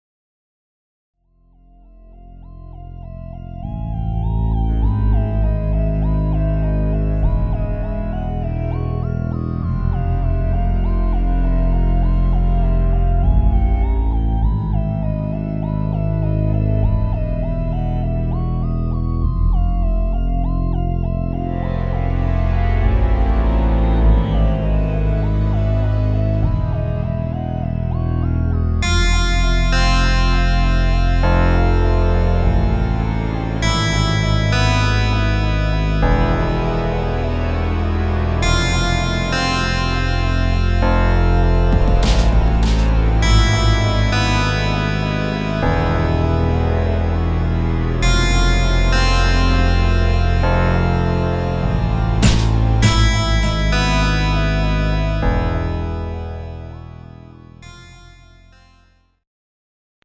Dark, eerie synth-based retro horror texture.